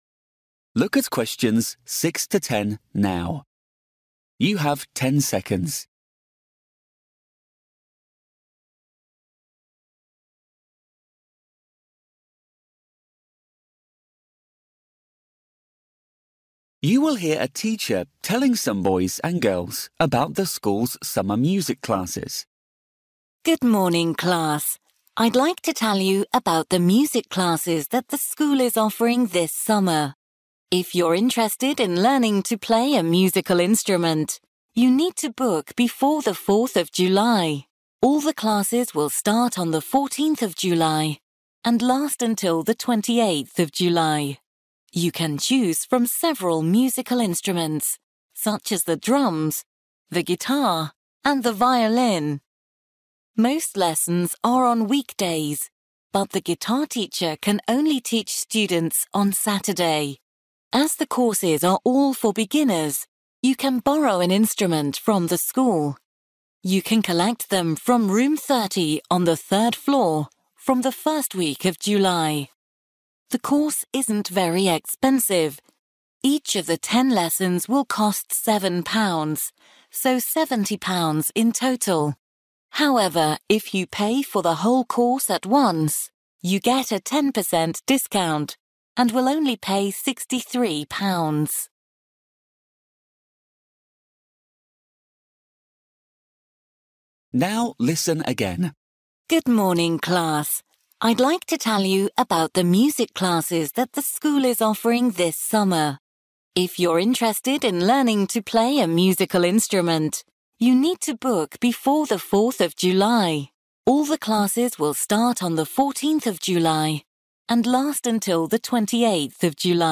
You will hear a teacher telling some boys and girls about the school’s summer music classes.